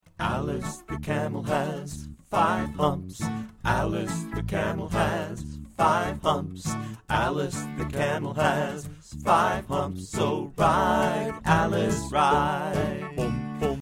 See more of our Folk Songs